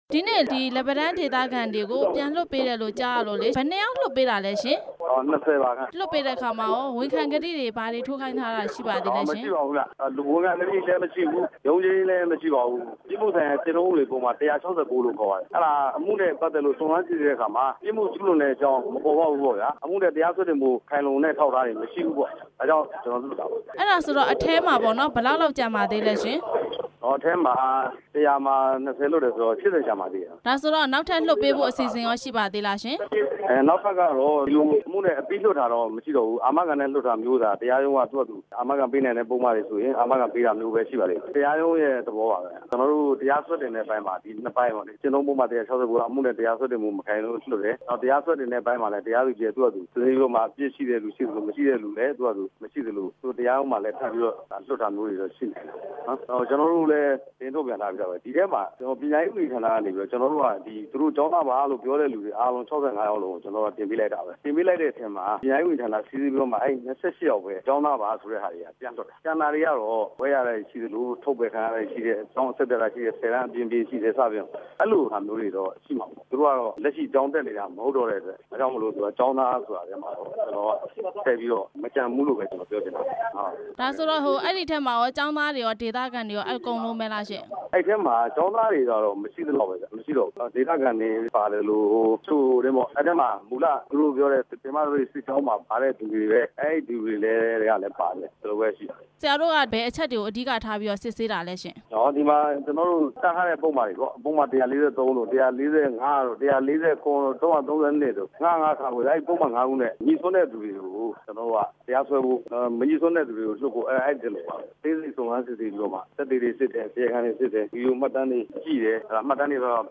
ပဲခူးတိုင်း ရဲတပ်ဖွဲ့ ရဲမှူးကြီးဝင်းစိန်ကို မေးမြန်းချက်